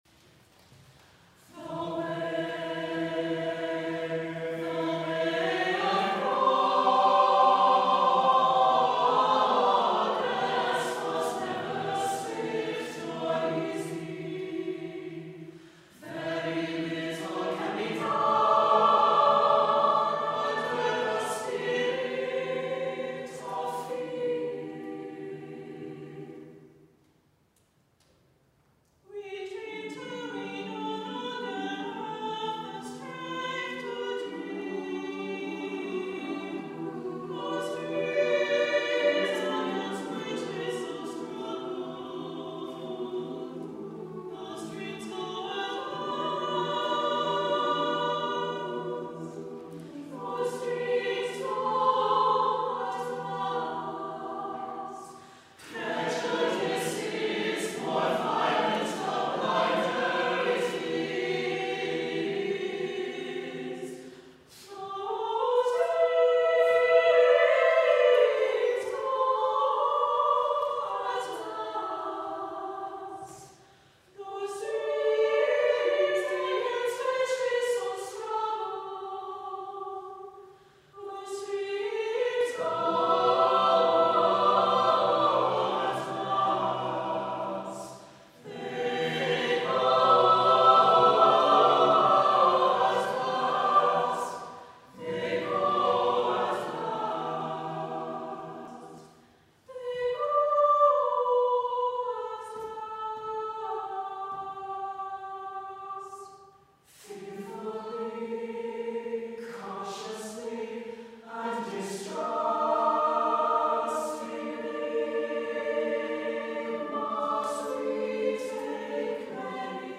A moving piece that uplifts voices of female scientists.
SATB a cappella, 2 treble soli